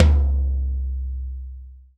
TOM TOM 78.wav